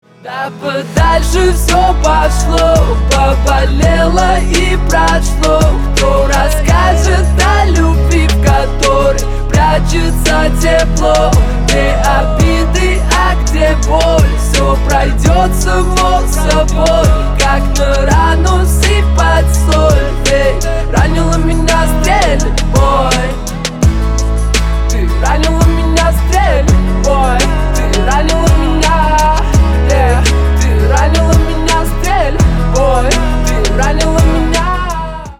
Поп Музыка # Рэп и Хип Хоп